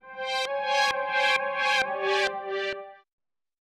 Index of /musicradar/uk-garage-samples/132bpm Lines n Loops/Synths